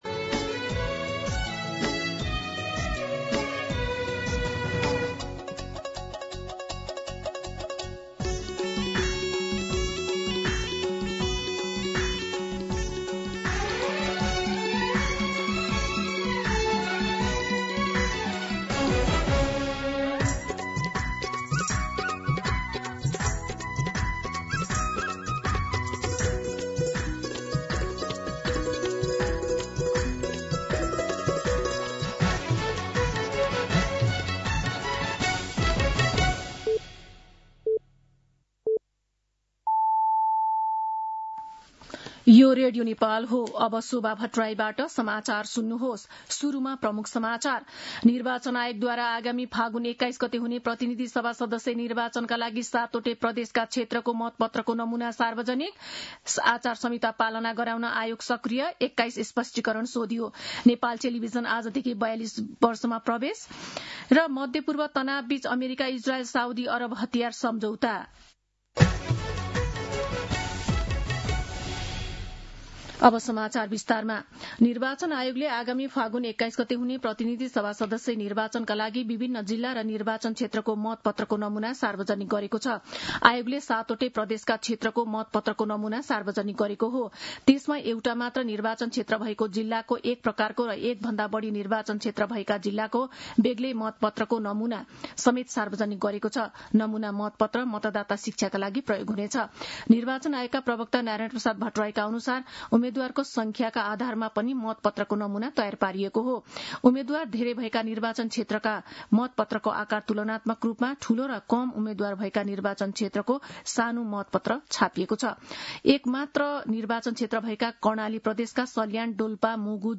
दिउँसो ३ बजेको नेपाली समाचार : १७ माघ , २०८२